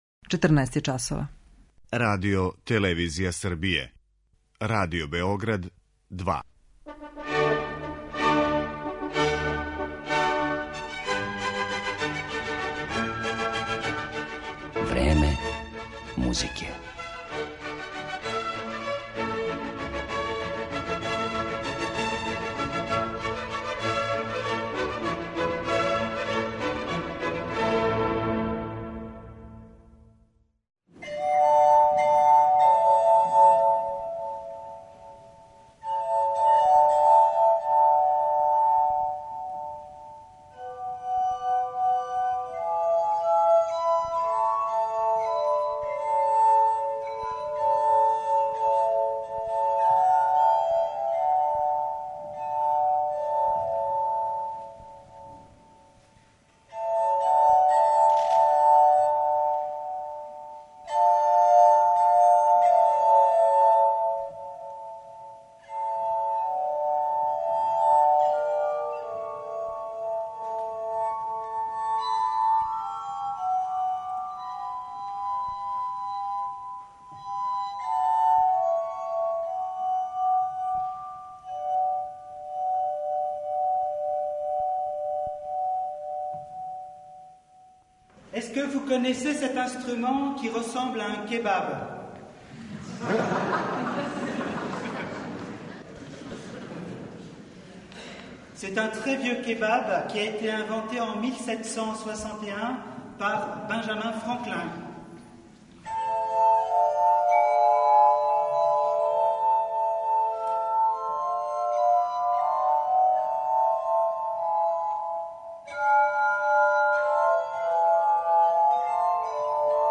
„Анђеоске оргуље” и мушки сопран
Слушаћете инструмент који је настао као плод инспирације свирања на чашама, гласхармонику, и прави мушки сопран.